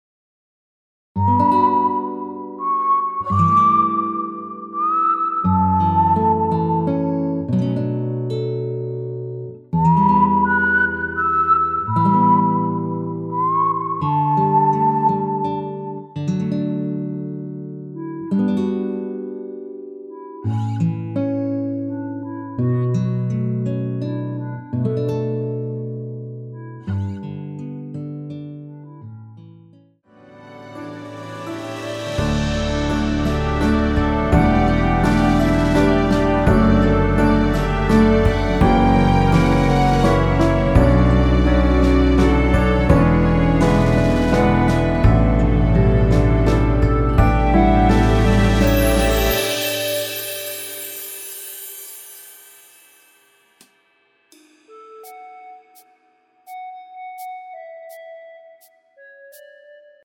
남성분이 부르실 수 있는 키의 MR입니다.
무반주 구간 들어가는 부분과 박자 맞출수 있게 쉐이커로 박자 넣어 놓았습니다.(미리듣기 확인)
원키에서(-6)내린 멜로디 포함된 MR입니다.(미리듣기 확인)
앞부분30초, 뒷부분30초씩 편집해서 올려 드리고 있습니다.
중간에 음이 끈어지고 다시 나오는 이유는